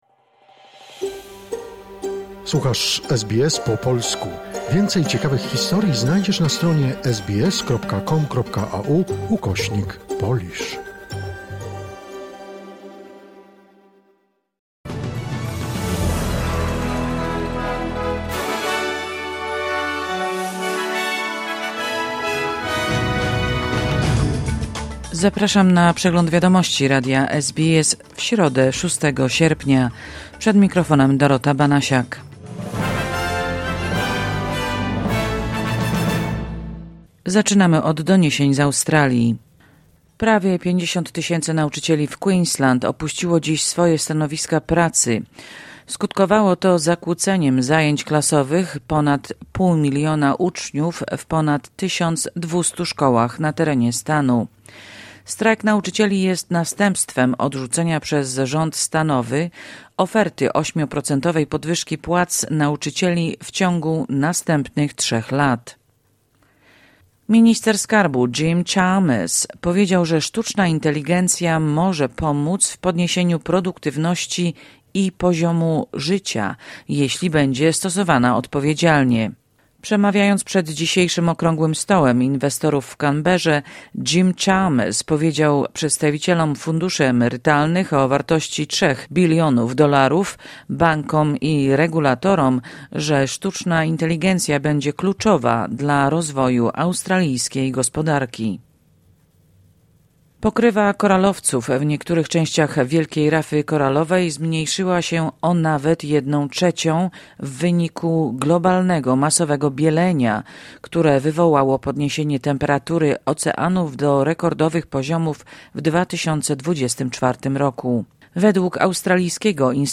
Wiadomości 6 sierpnia SBS News Flash